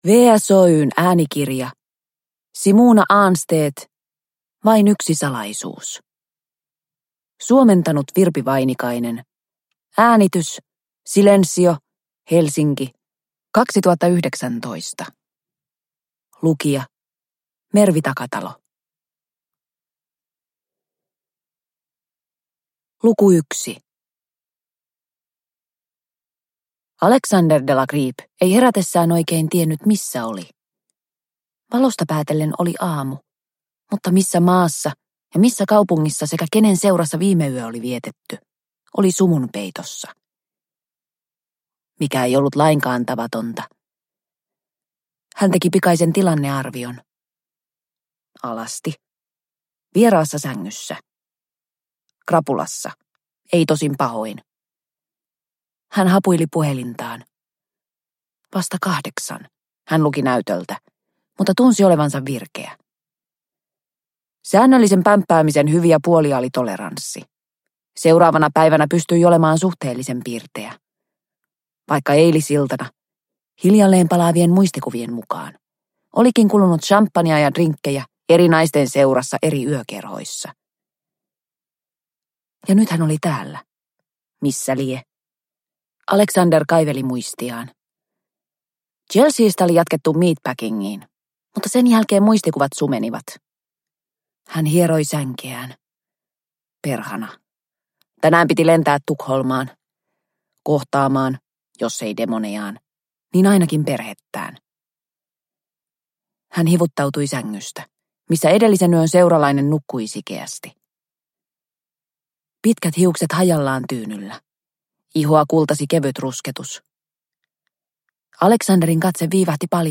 Vain yksi salaisuus – Ljudbok – Laddas ner